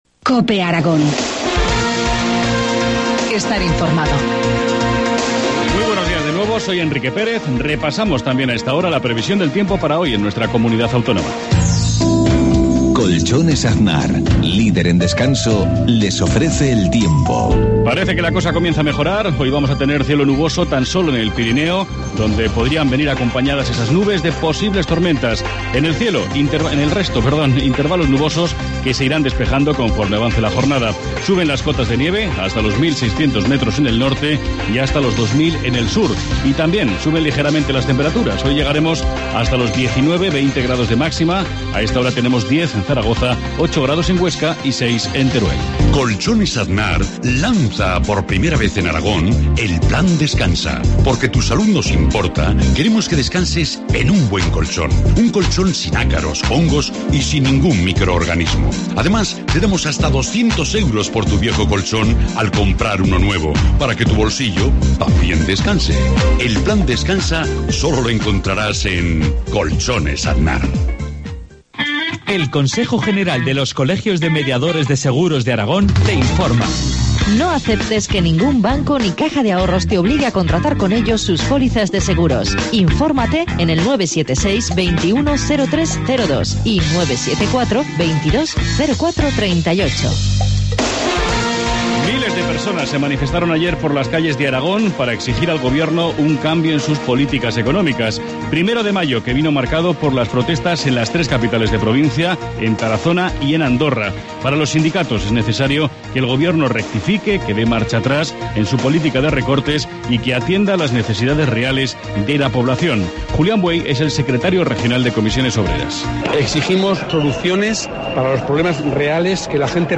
Informativo matinal, jueves 2 de mayo, 7.53 horas